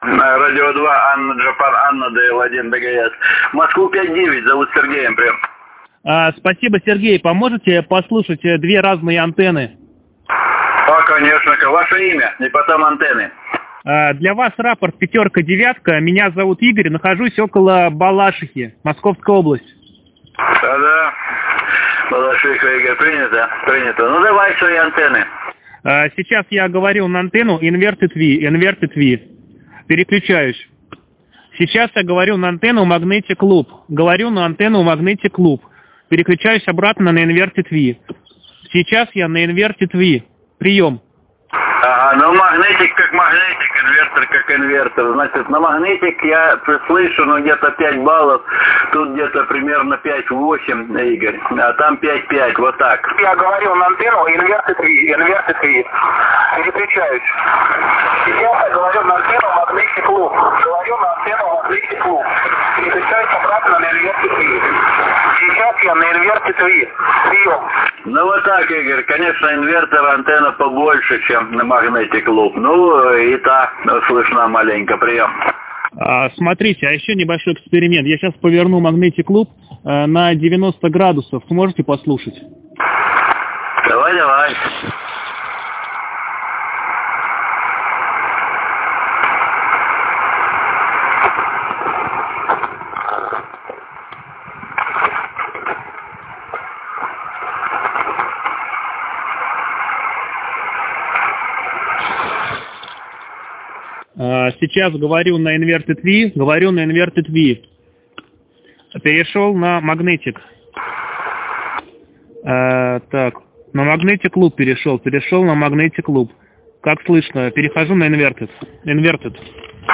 3) Оценка сигнала другим оператором в режиме SSB.
В основном при переходе на Inv-V усиливаются шумы и уровень сигналов на 1-3 балла.
В процессе поворота на 90 градусов ушла настройка, перенастроил по КСВ-метру (слышно в аудио).